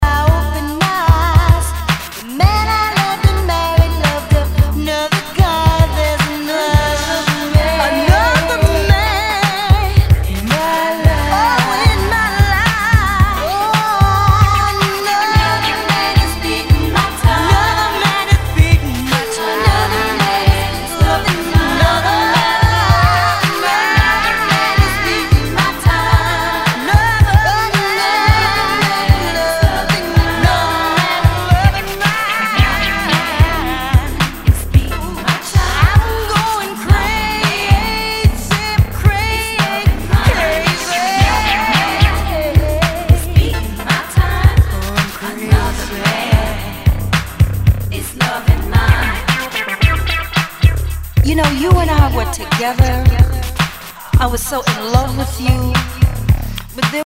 SOUL/FUNK/DISCO
ナイス！ダンス・クラシック！